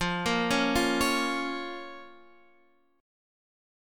Fsus4#5 chord